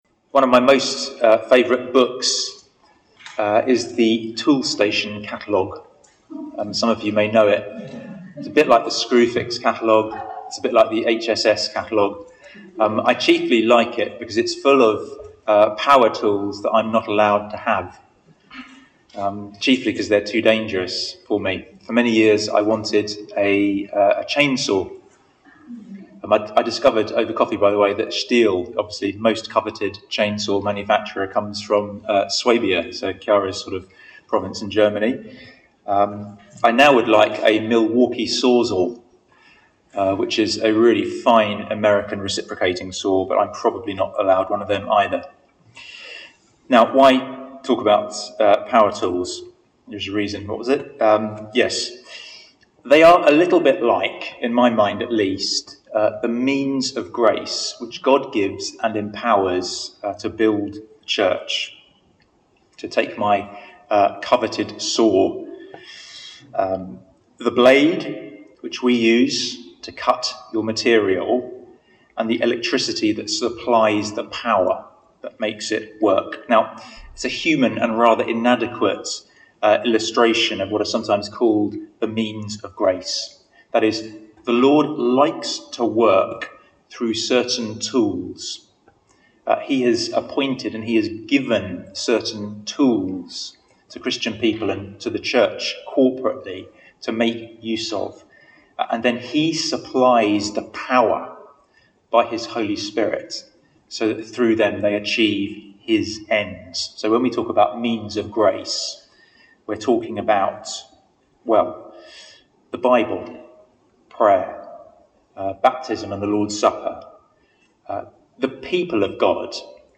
Away Day 2021 Service Type: Conference « 2.